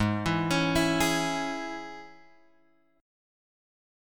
G# Suspended 4th Sharp 5th